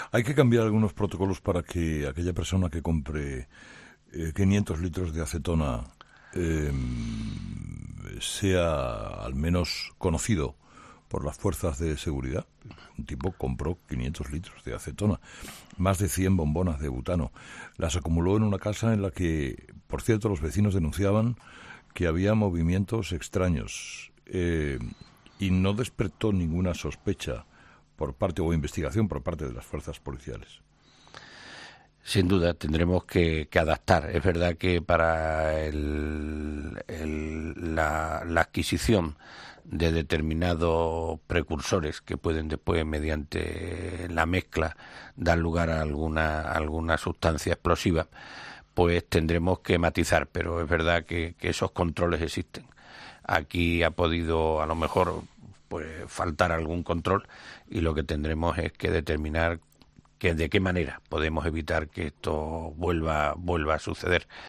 El ministro del Interior habla en COPE sobre el atentado terrorista del 17 de agosto en Barcelona que cotó la vida a 16 personas.